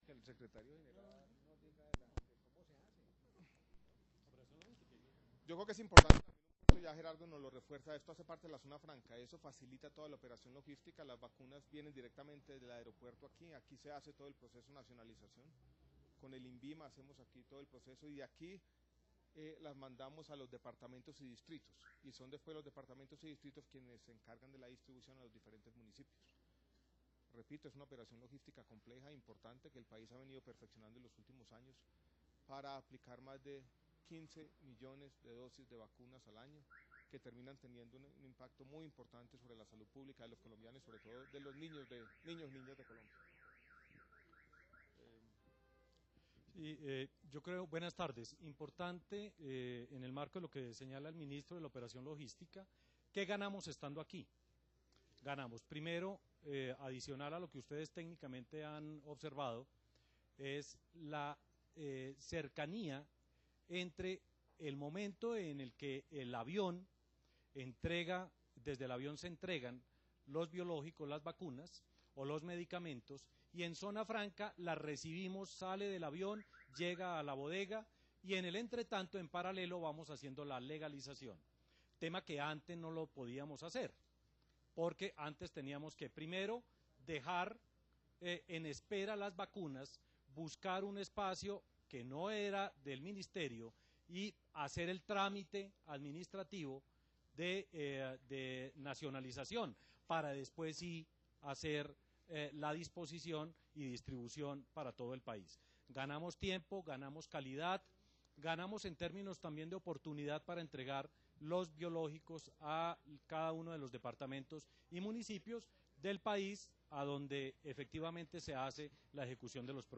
- Audio: declaraciones del ministro Alejandro Gaviria durante la inauguración de la bodega